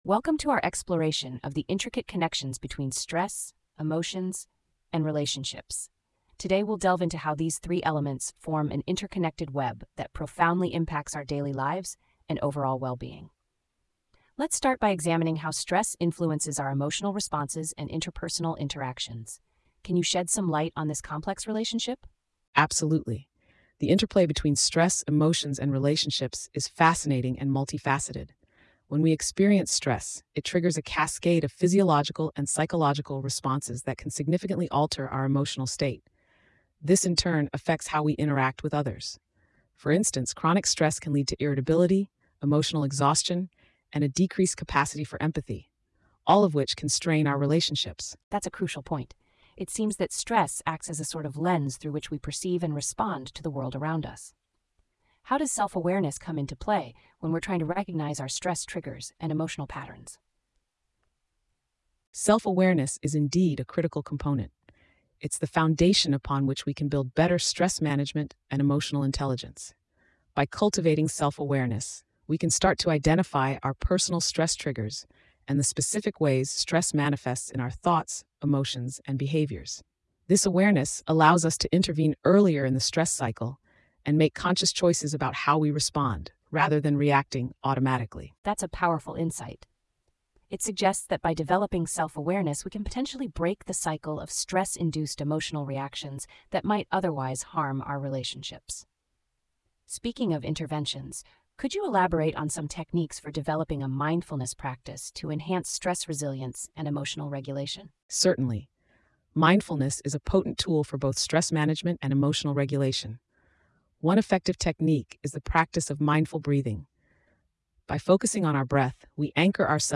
PodWaveAI - AI-Powered Podcast Generator